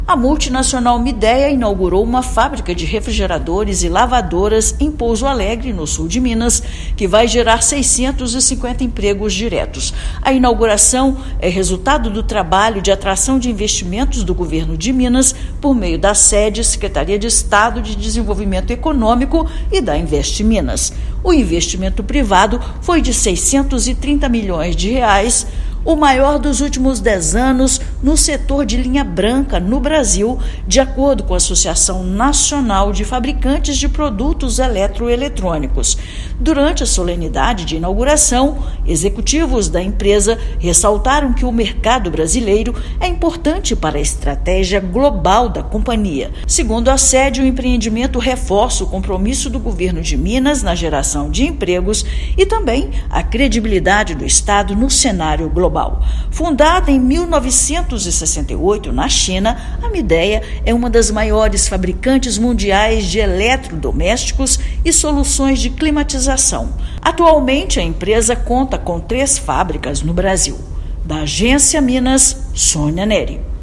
Trabalho do Governo de Minas atrai empresa focada na fabricação de eletrodomésticos, com investimento de R$ 630 milhões. Ouça matéria de rádio.